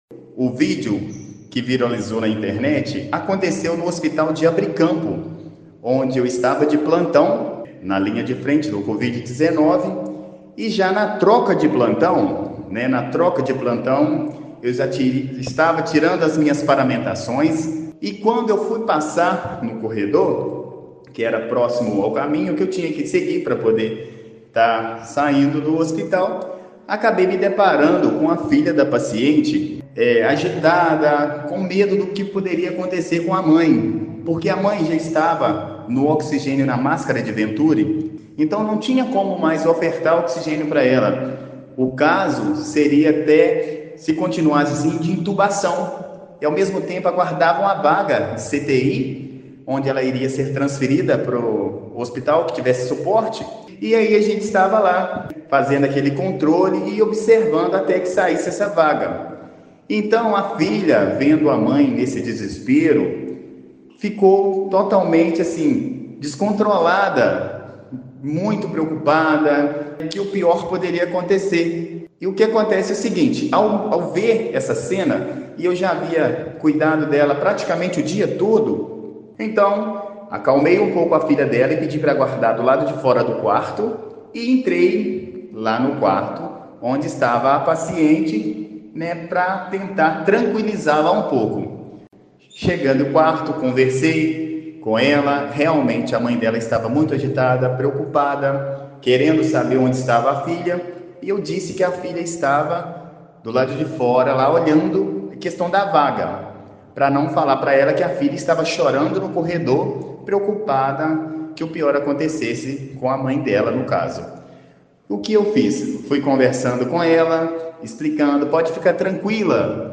Em entrevista a Rádio Manhuaçu e jornal Tribuna do Leste